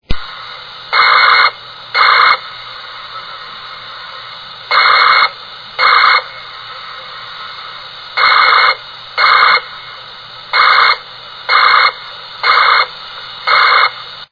5 November, 2000 - data bursts on the voice link
Data bursts similar to those picked up from mir were heard on 143.625 MHz.
issburst.mp3